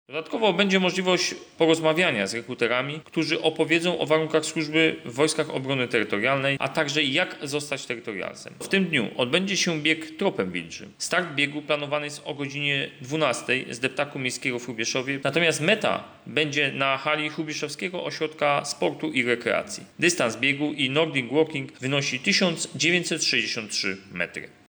Funkcjonariusze zaprezentują zarówno sprzęt bojowy jak i ten używany w sytuacjach kryzysowych – zapowiada oficer prasowy 2.